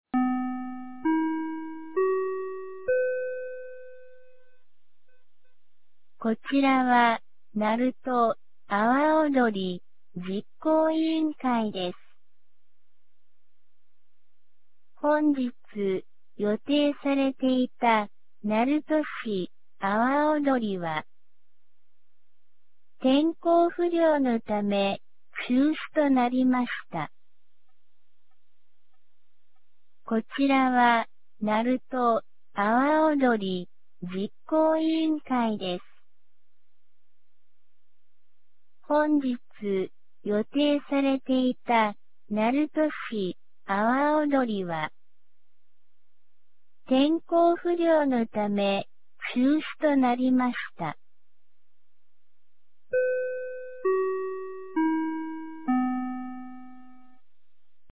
2025年08月10日 17時46分に、鳴門市より全地区へ放送がありました。